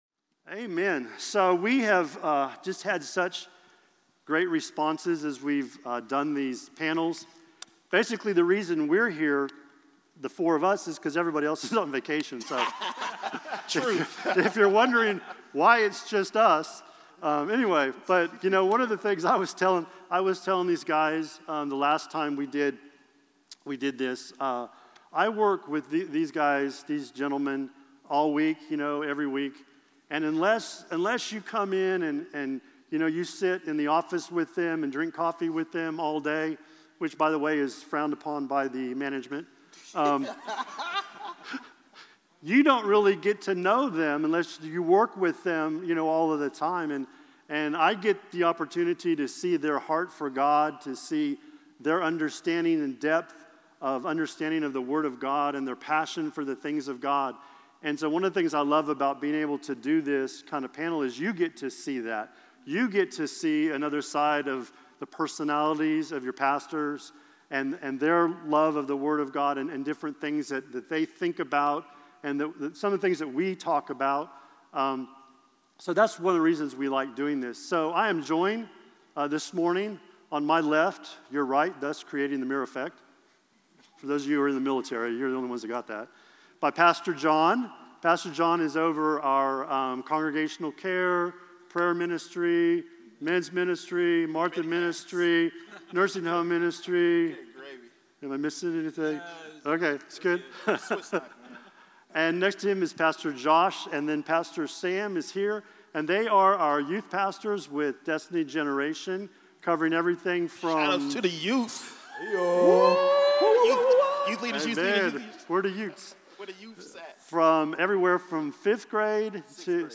CL Panel